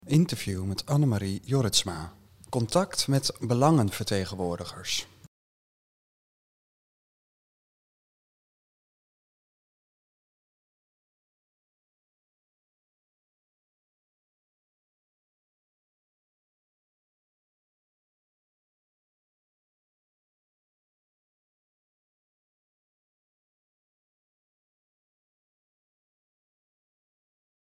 Interview met Annemarie Jorritsma